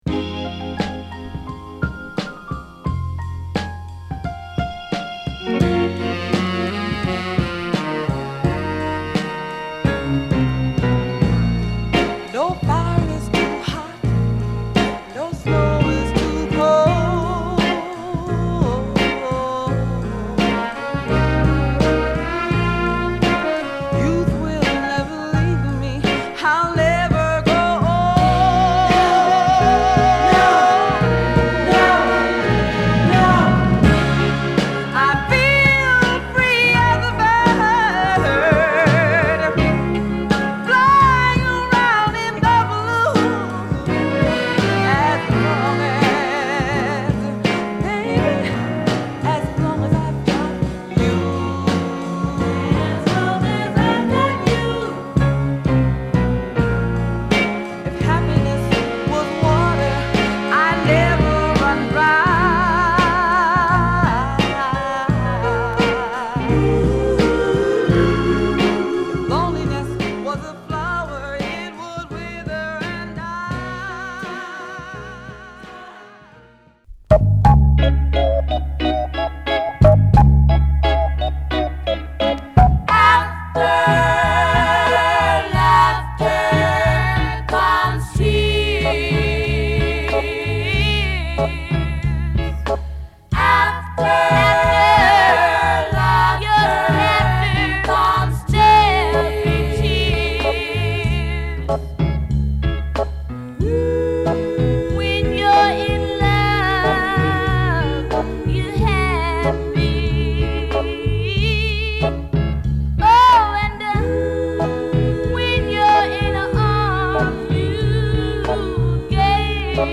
哀愁のピアノ＆ホーンが響くド渋なサザン・ソウル